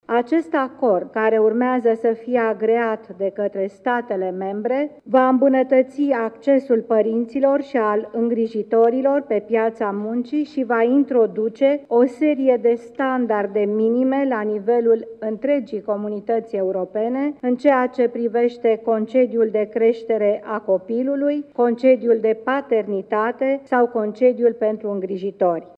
Anunţul a fost făcut de premierul Viorica Dăncilă în deschiderea şedinţei de guvern de astăzi: